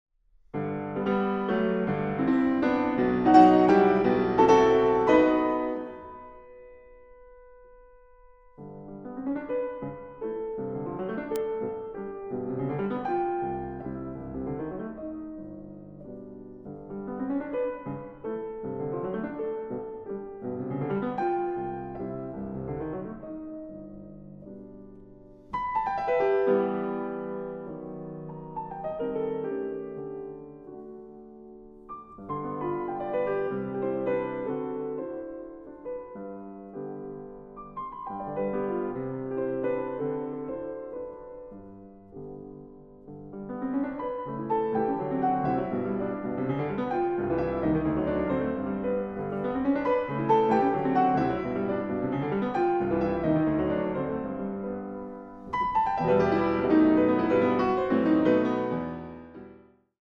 Klavier